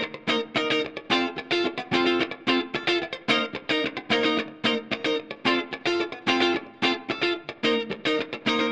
30 Guitar PT2.wav